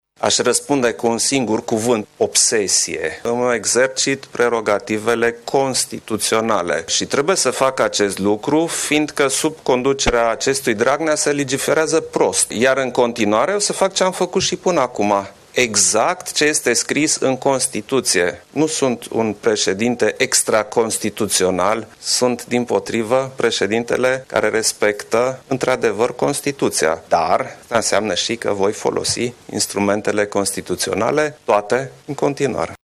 Cu ocazia conferinţei de presă susţinută, joi, 12 iulie, la Bruxelles, după Summit-ul NATO, preşedintele Klaus Johannis a apreciat că ideea suspendării sale a devenit o obsesie a liderului social democrat. El a răspuns întrebării unui jurnalist, referitoare la afirmaţiile preşedintelui PSD, Liviu Dragnea: